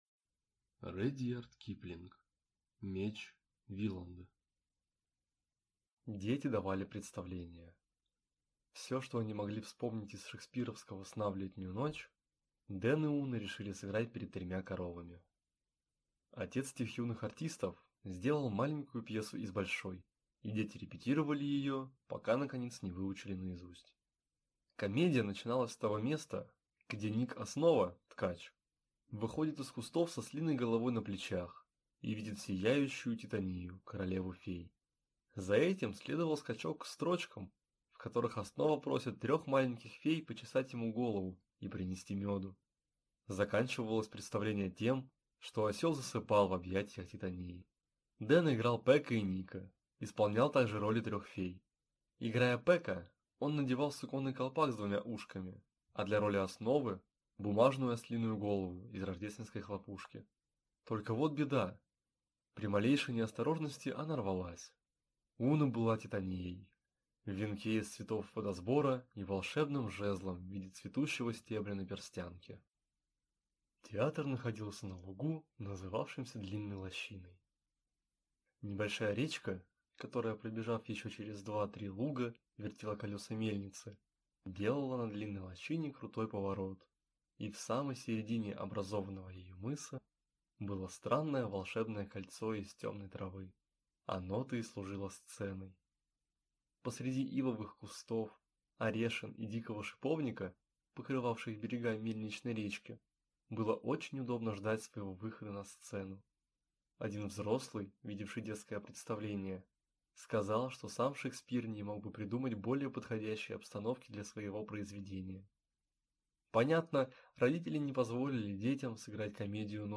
Аудиокнига Меч Виланда | Библиотека аудиокниг